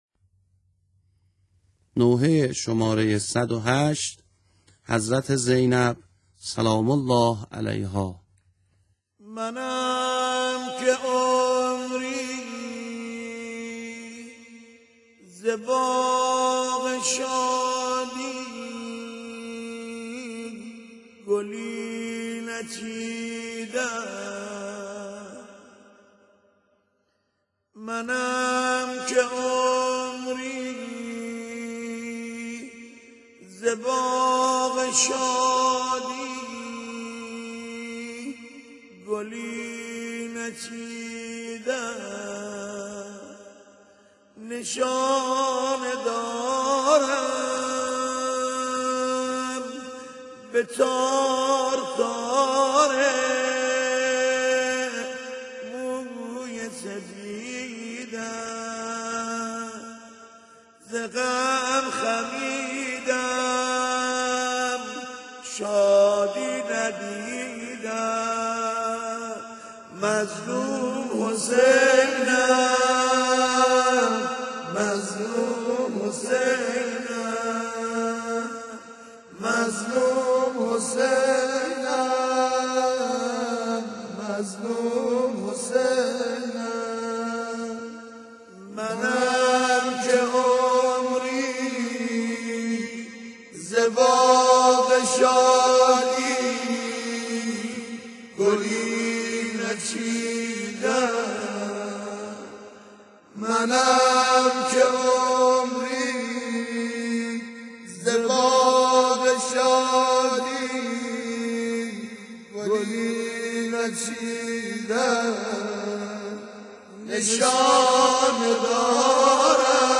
اشعار وفات حضرت زینب کبری(س),(منم که عمری، ز باغ شادی، گلی نچیدم)به همراه سبک سنتی